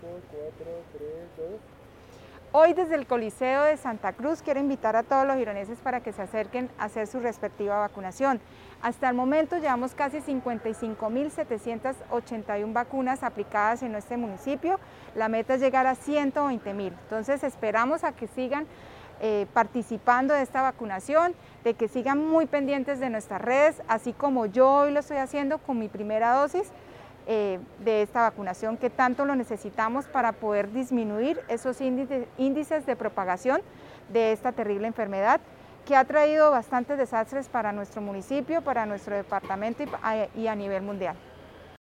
Yulia Rodríguez, Alcaldesa de Girón.mp3